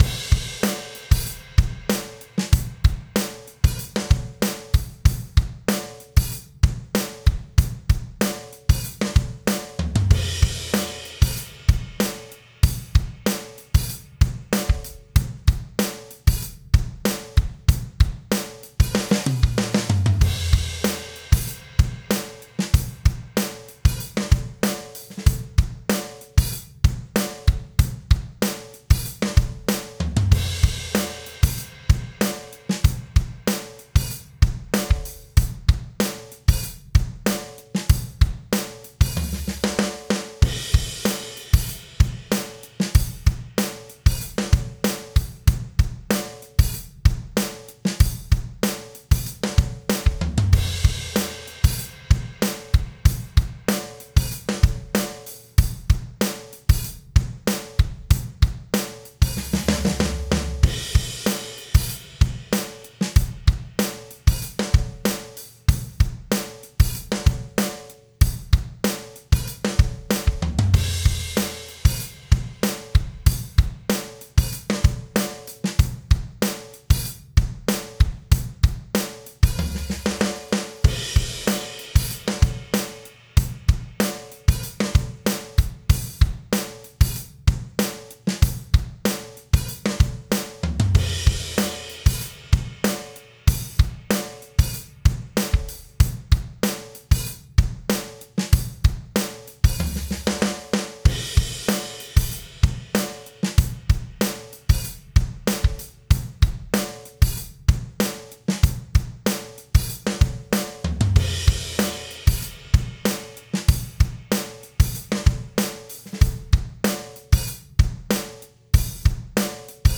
Guitar Lesson - Rhythm Guitar